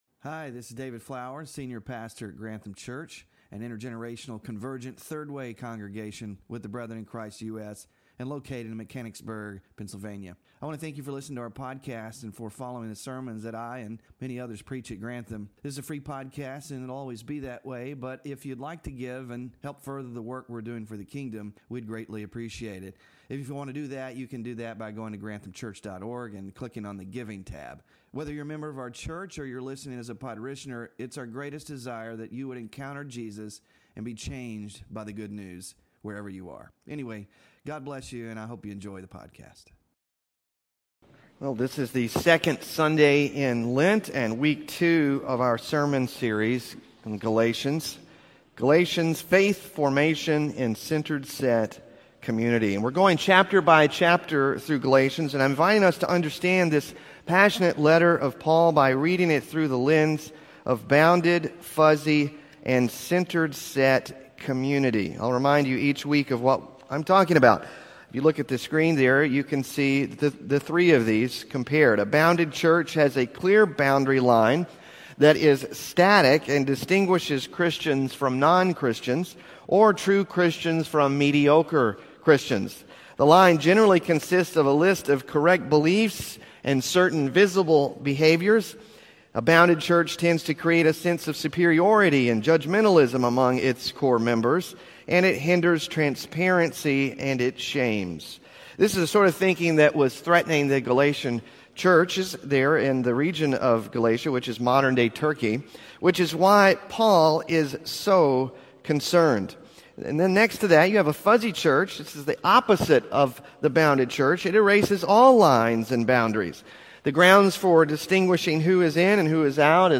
WORSHIP RESOURCES CONFRONTING BOUNDED THINKING SERMON SLIDES (2 of 6) SMALL GROUP QUESTIONS (3-16-25) BULLETIN (3-16-25)